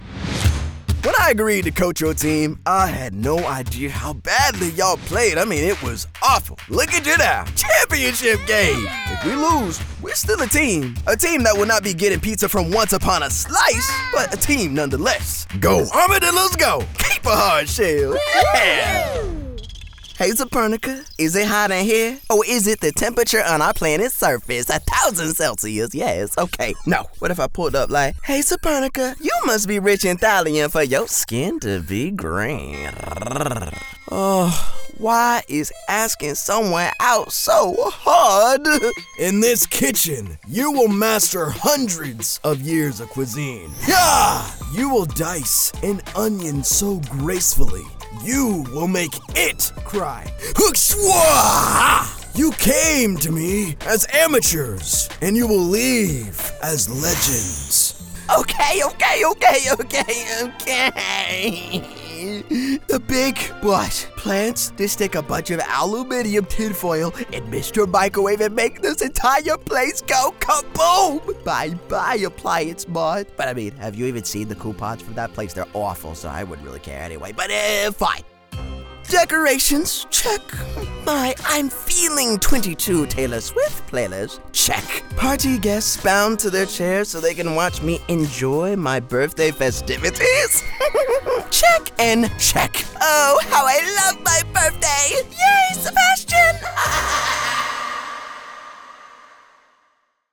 Engels (Amerikaans)
Jong, Stedelijk, Stoer, Veelzijdig, Vriendelijk
Telefonie